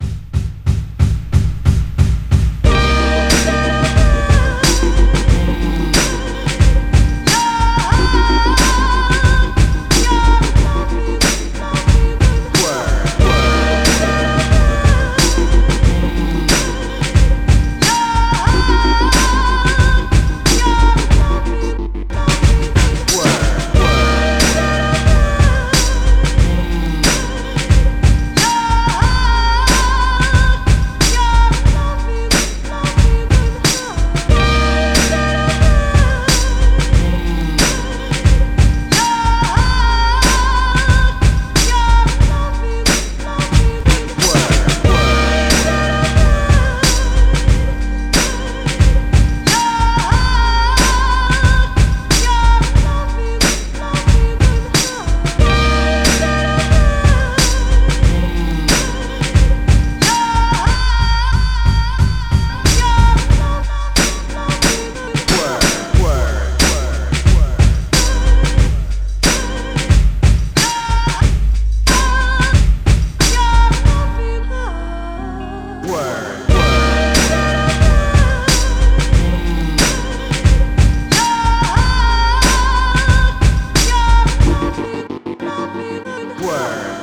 (Instrumental Project)
Genre: Hip-Hop.